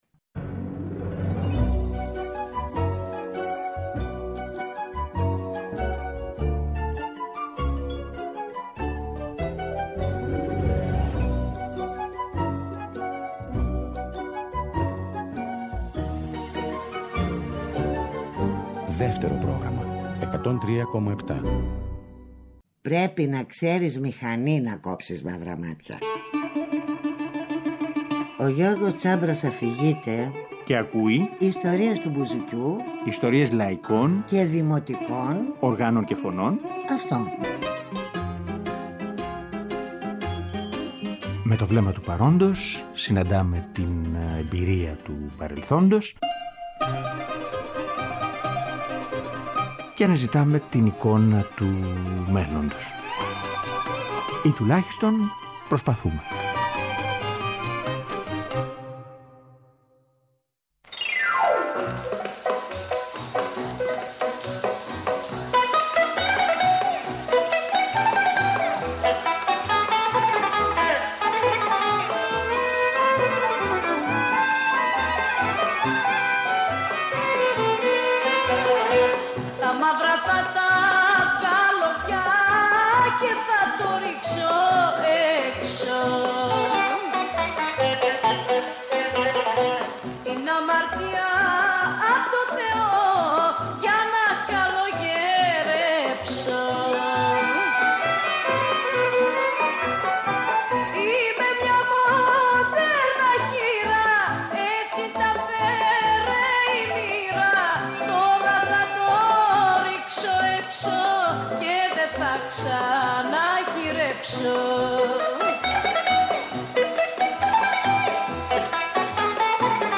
Και φυσικά, οι σχετικές πληροφορίες, αφηγήσεις, σκέψεις.